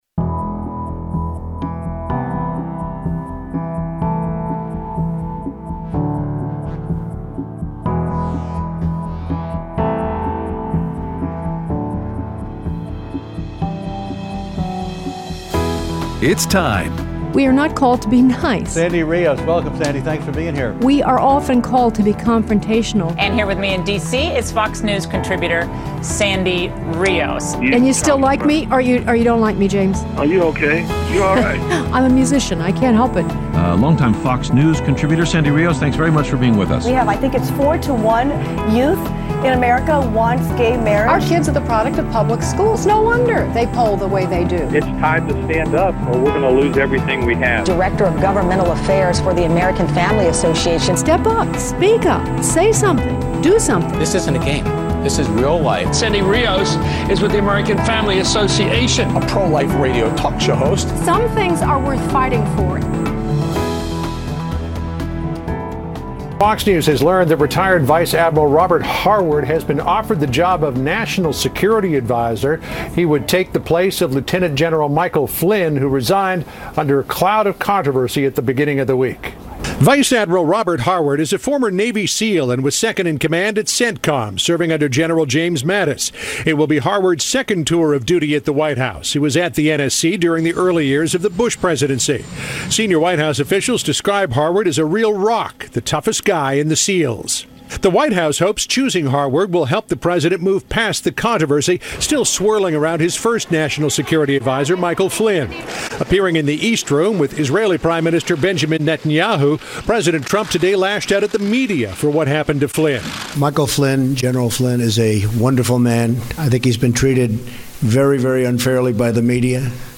Aired Thursday 2/16/17 on AFR 7:05AM - 8:00AM CST